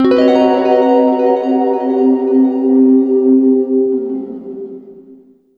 GUITARFX12-L.wav